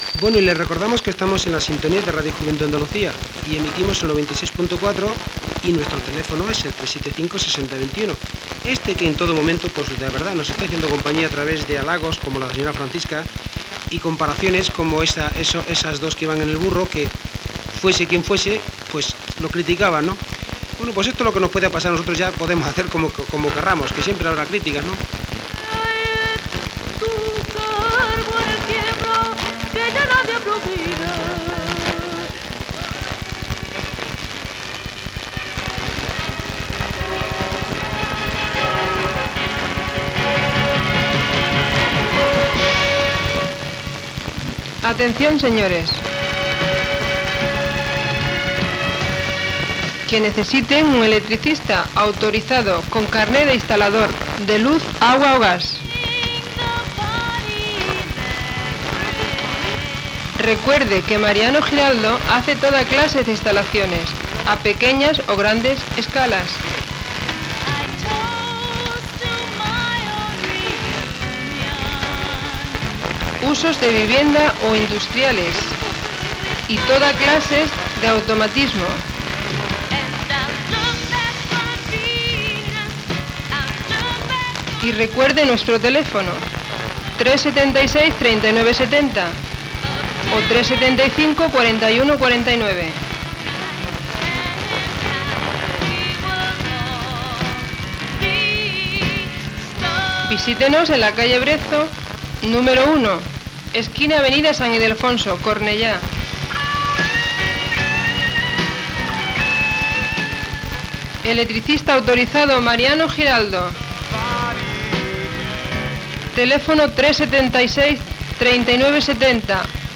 Identificació i publicitat
FM